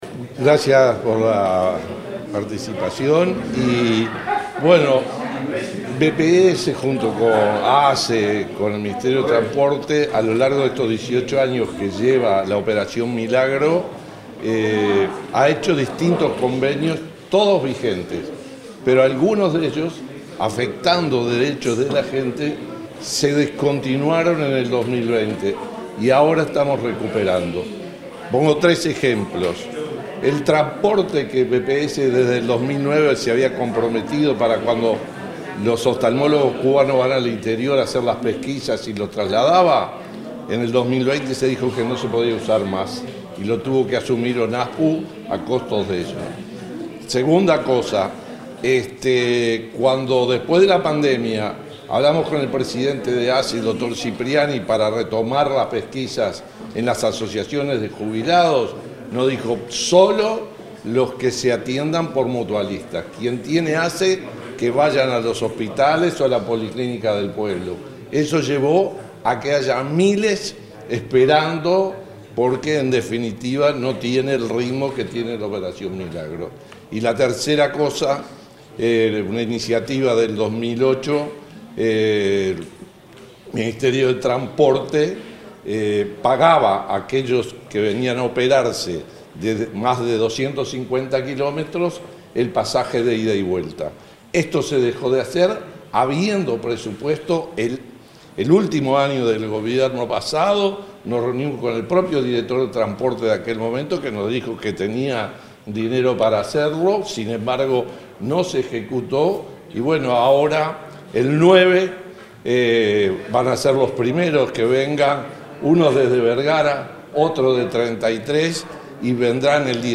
Declaraciones del director del BPS, Ariel Ferrari 22/08/2025 Compartir Facebook X Copiar enlace WhatsApp LinkedIn En el marco de la conferencia de prensa para anunciar la reinstalación de los beneficios a jubilados y pensionistas, el director del Banco de Previsión Social, Ariel Ferrari, realizó declaraciones a la prensa.